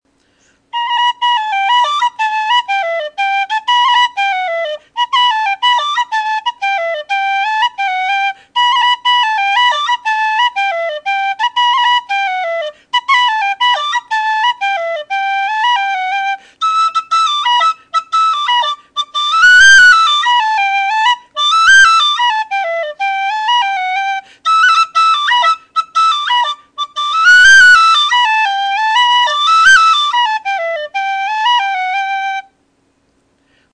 Whistle Reviewed: Keltic Dead Soprano D whistle
Playing Characteristics This whistle has a nice complex tone. It has what I would consider to be the perfect amount of start-of-note chiff, crisp and accenting the note nicely without being airy, hissy, or even very noticeable unless you’re listening for it.
Sound clip of the whistle: